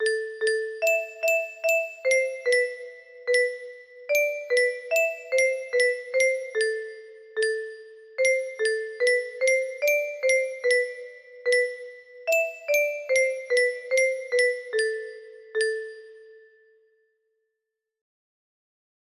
Nuku Nuku Hiiripieni music box melody